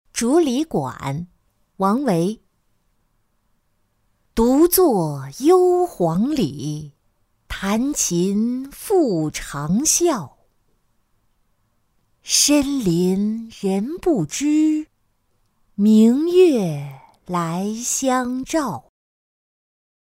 竹里馆-音频朗读